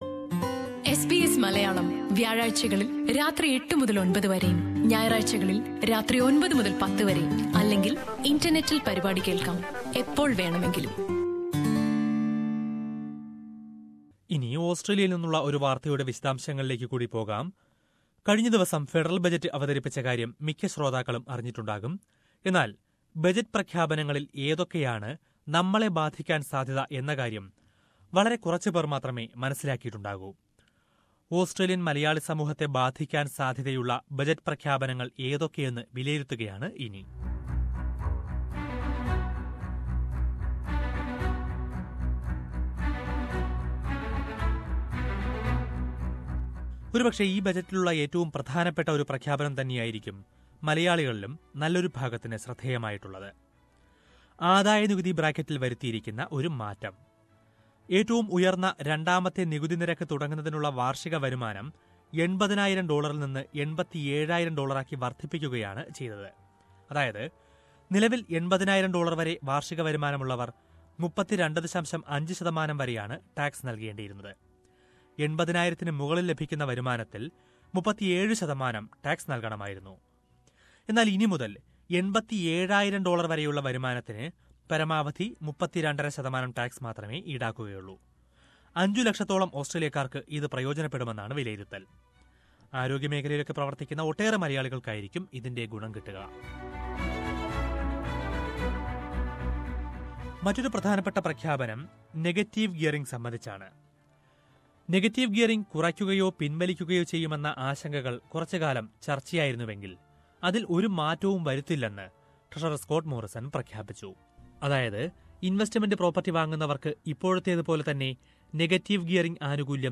മാൽക്കം ടേൺബുൾ സർക്കാരിൻറെ ആദ്യത്തെ പൊതുബജറ്റ് കഴിഞ്ഞയാഴ്ച അവതരിപ്പിച്ചു. തെരഞ്ഞെടുപ്പ് പ്രഖ്യാപനത്തിന് തൊട്ടുമുന്പുള്ള ഈ ബജറ്റിലെ ഏതൊക്കെ പ്രഖ്യാപനങ്ങളായിരിക്കും ഓസ്ട്രേലിയൻ മലയാളികളെ ബാധിക്കുക? ഇക്കാര്യം വിശദമായി പരിശോധിക്കുന്ന റിപ്പോർട്ട് കേൾക്കാം, മുകളിലെ പ്ലേയറിൽ നിന്നും...